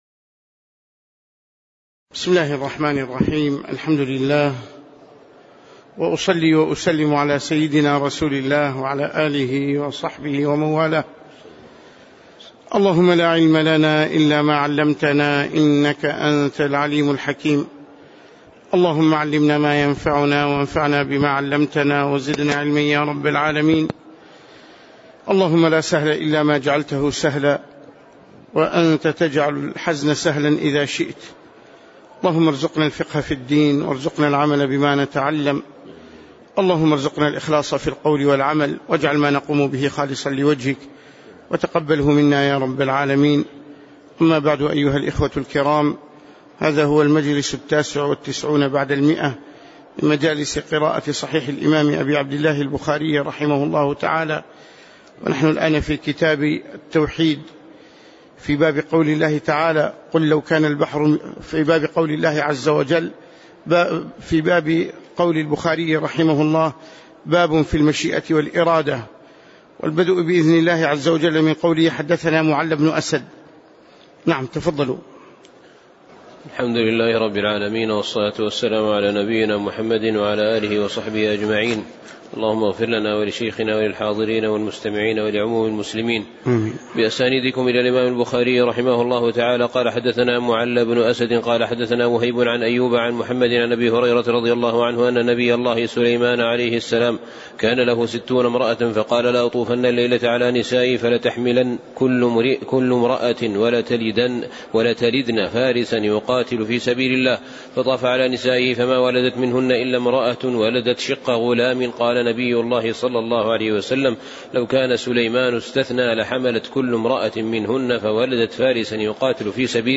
تاريخ النشر ١٨ جمادى الأولى ١٤٣٩ هـ المكان: المسجد النبوي الشيخ